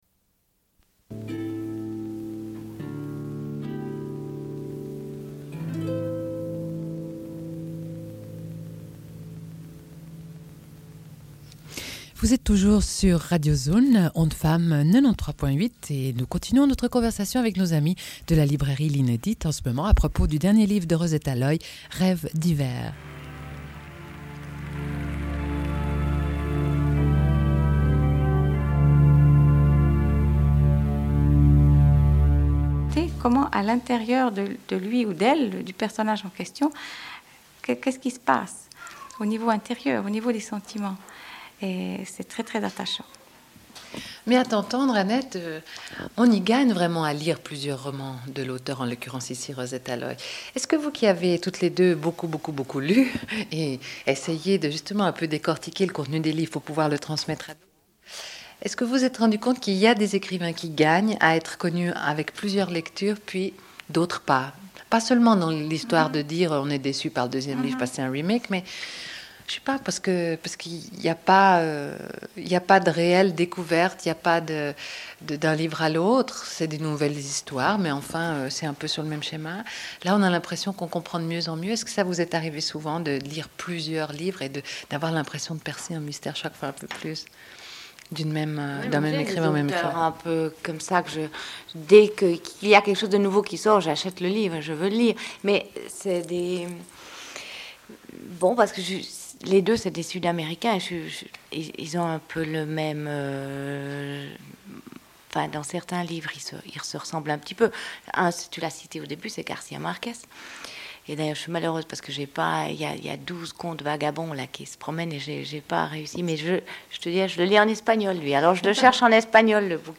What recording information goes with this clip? Une cassette audio, face A31:13